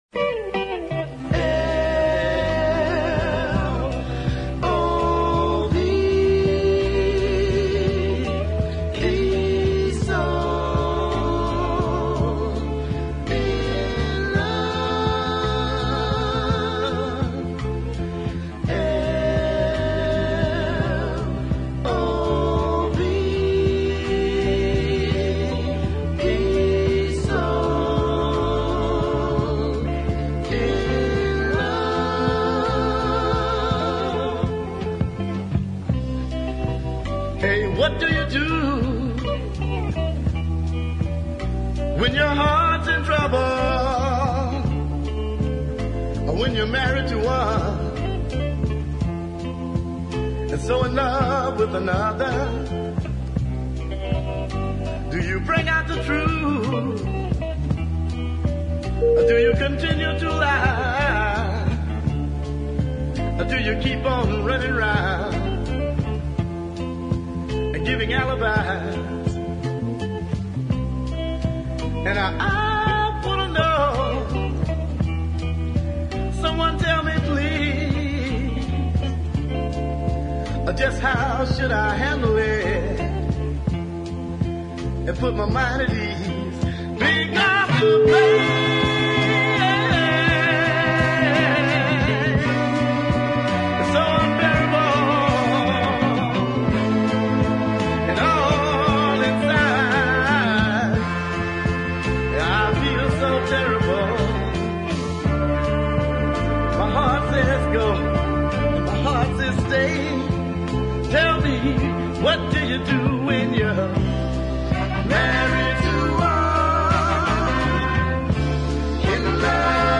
beautifully sung